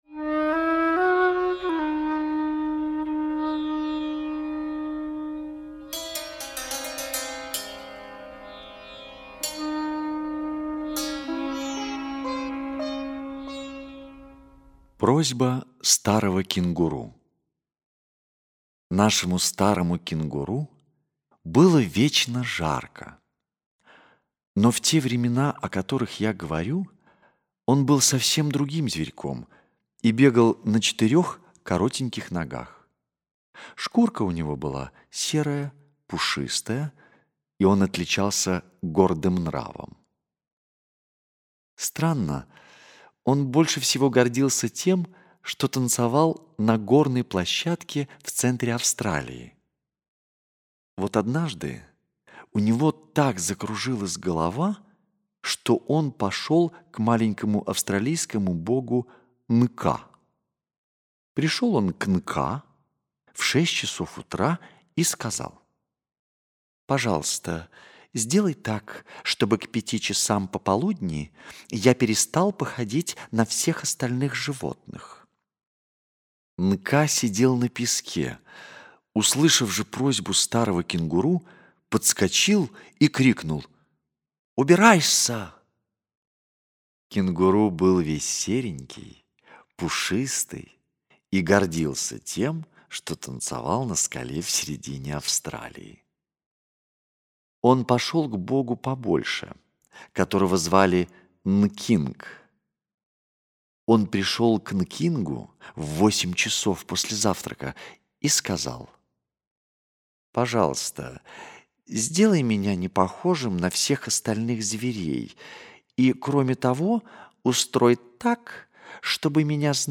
Просьба старого кенгуру - аудиосказка Киплинга - слушать